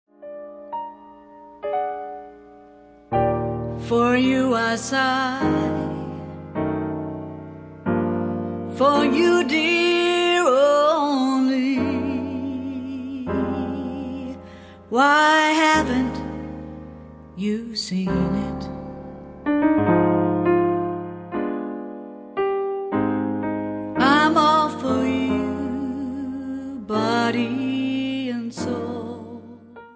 NOTE: Background Tracks 1 Thru 10